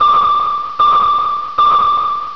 ASDIC contact, breaking up attack, setting boat on ground 175m depth.